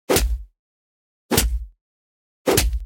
دانلود آهنگ دعوا 23 از افکت صوتی انسان و موجودات زنده
دانلود صدای دعوا 23 از ساعد نیوز با لینک مستقیم و کیفیت بالا
جلوه های صوتی